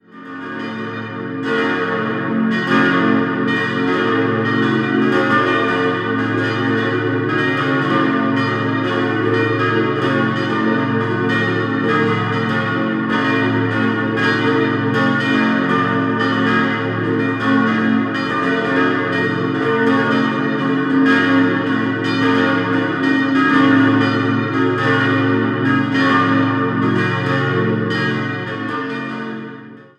Die Pfarrkirche wurde ab 1790 errichtet und in den Jahren 1926/27 erweitert. 5-stimmiges Geläut: b°-des'-es'-ges'-b' Die mittlere Glocke wurde 1903, die anderen 1955 von der Gießerei Rüetschi in Aarau gegossen.